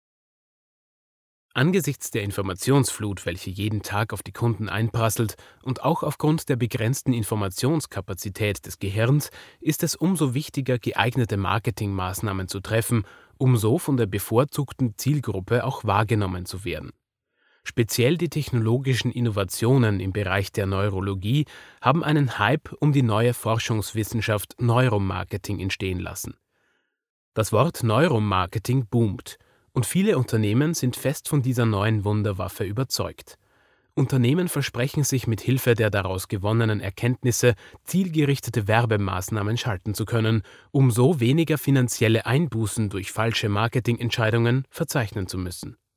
E-Learning & Corp. Podcast
DEMO_Referenz_2020_E-Learning_2.mp3